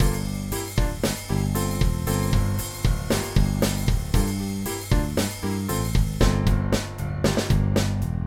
transformer-RD-Fat-Bass-Gtr
transformer-RD-Fat-Bass-Gtr.mp3